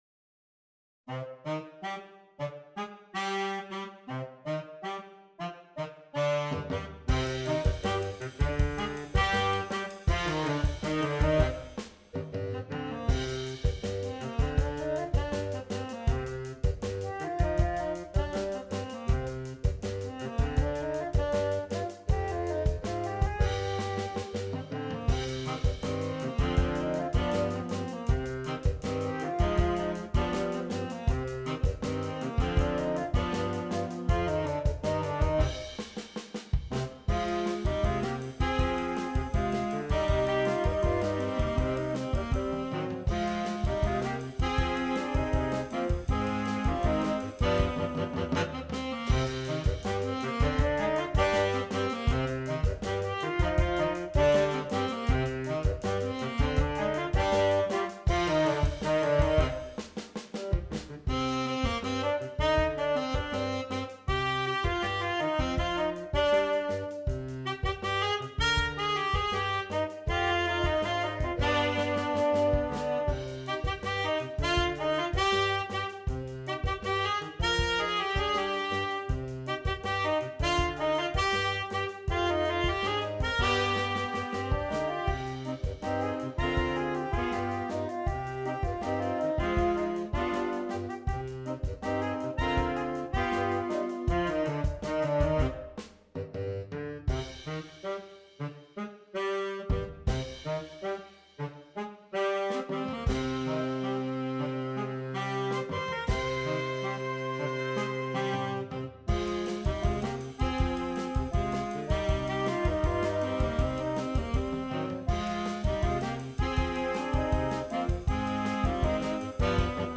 Saxophone Quartet (AATB) Optional Drums